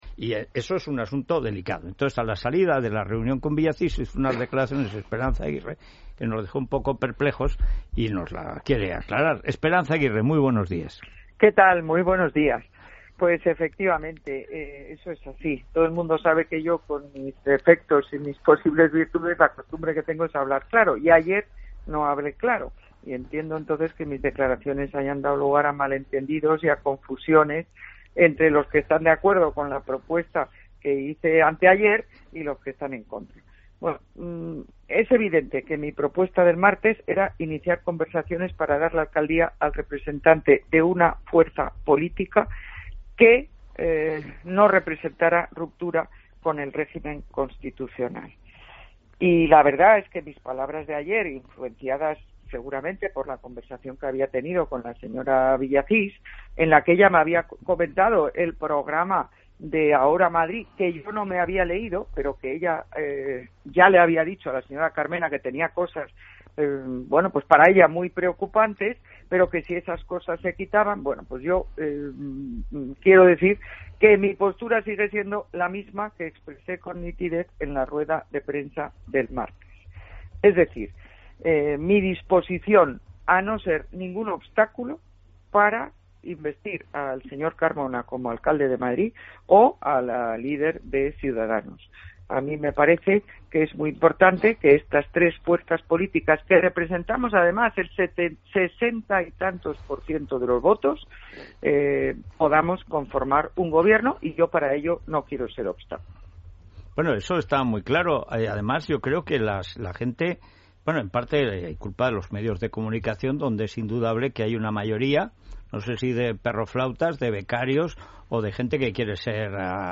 La presidenta del PP madrileño ha sido entrevistada por Federico Jiménez Losantos. Según ella misma, la radio de los obispos le pagó «alrededor de 10.000€» por sus colaboraciones.
federico-entrevista-a-esperanza-aguirre-88289.mp3